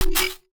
UIClick_Next Button 03.wav